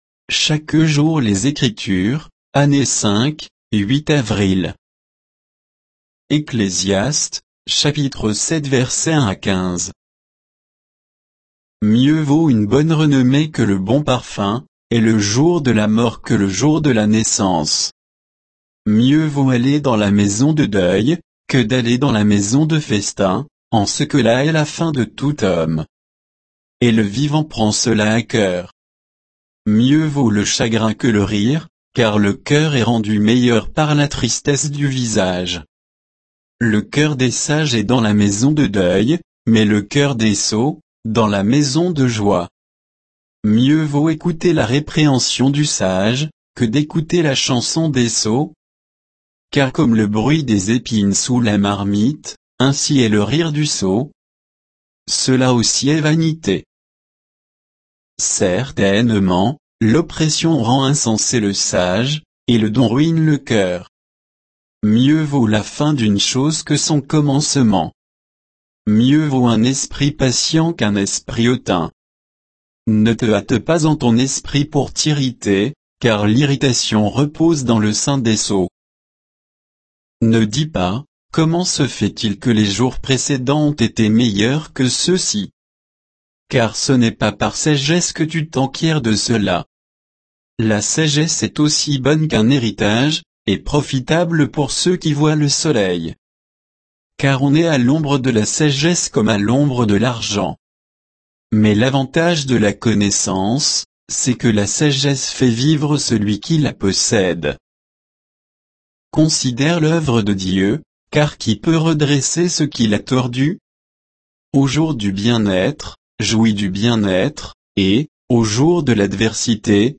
Méditation quoditienne de Chaque jour les Écritures sur Ecclésiaste 7, 1 à 15